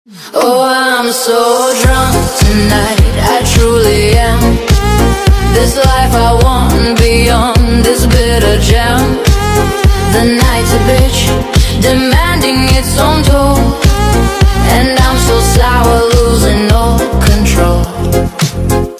танцевальные , поп